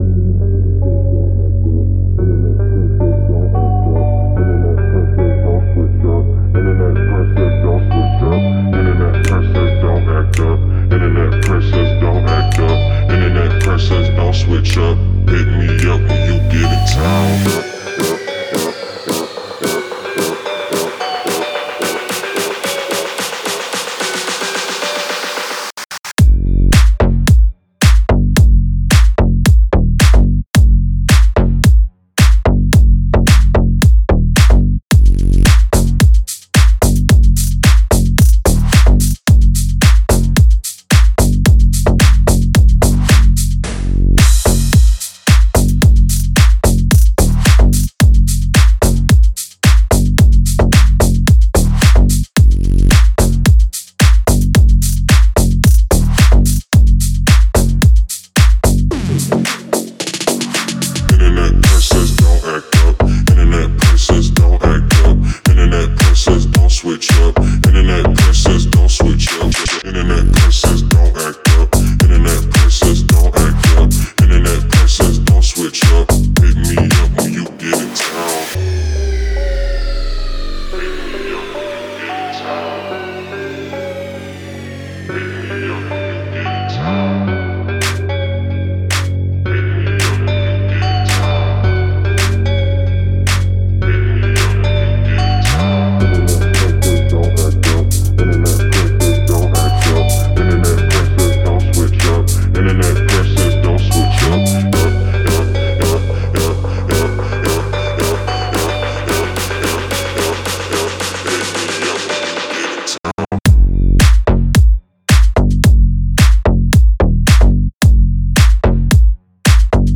в жанре EDM